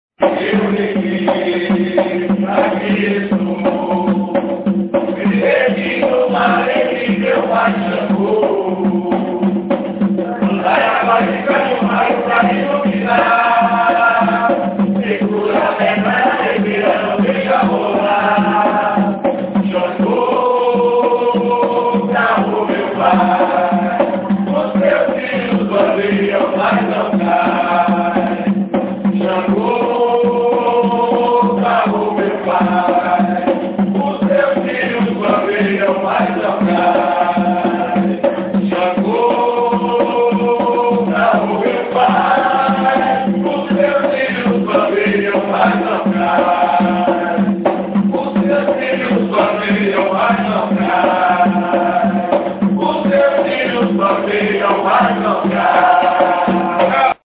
Ensaio Gira – Casa Vó Maria